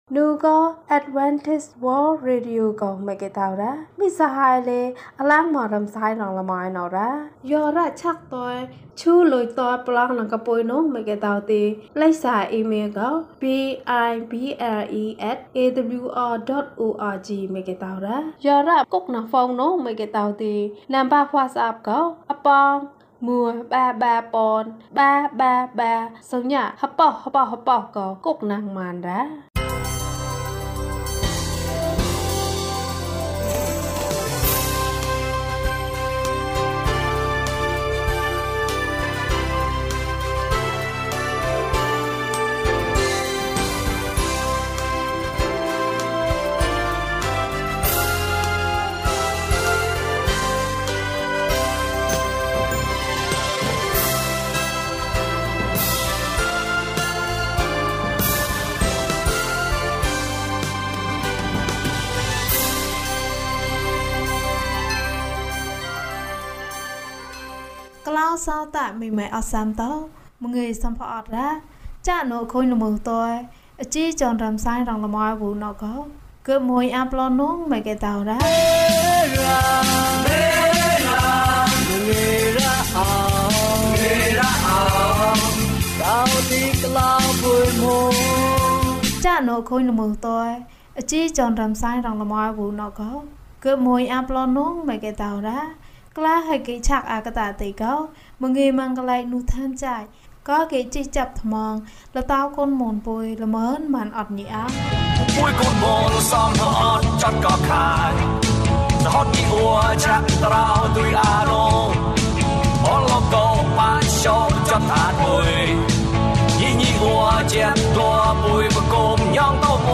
သခင်ယေရှု။ ကျန်းမာခြင်းအကြောင်းအရာ။ ဓမ္မသီချင်း။ တရားဒေသနာ။